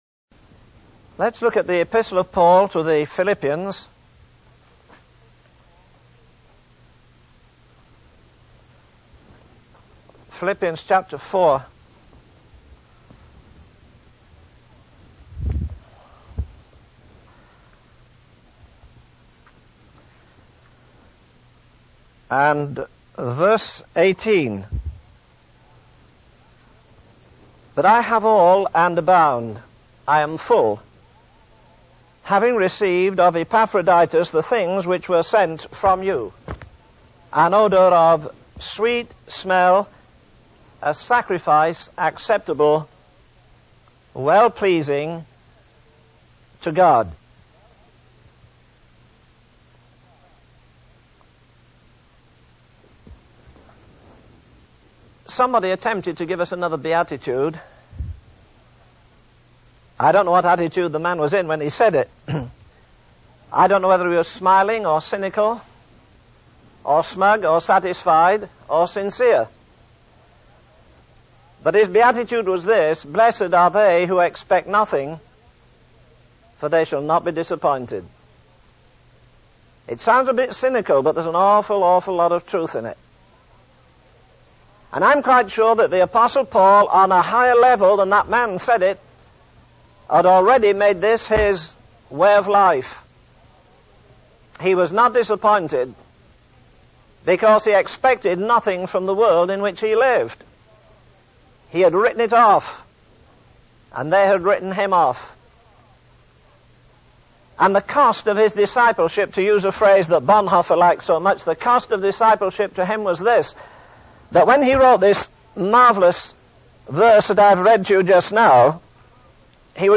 In this sermon, the preacher emphasizes the unbreakable love of Christ and the victory believers have in Him.